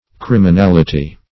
Criminality \Crim`i*nal"i*ty\ (kr?m`?-n?l"?-t?), n. [LL.